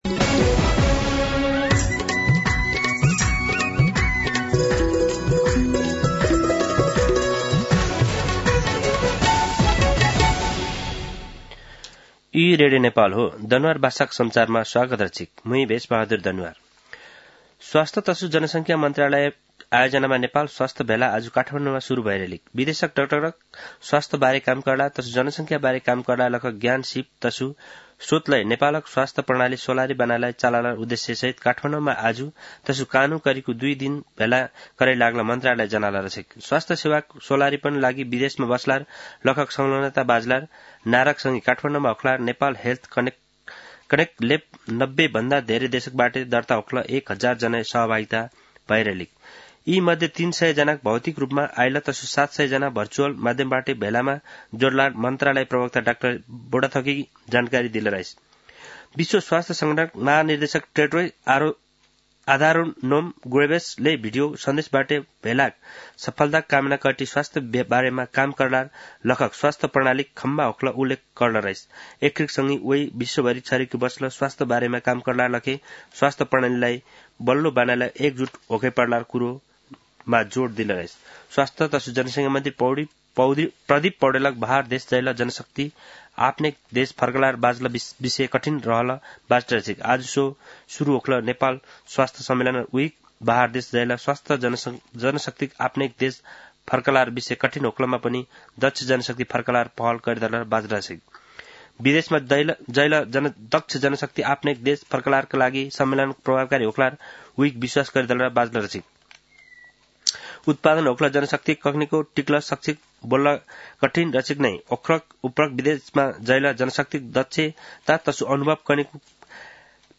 दनुवार भाषामा समाचार : १२ पुष , २०८१
Danuwar-News-1.mp3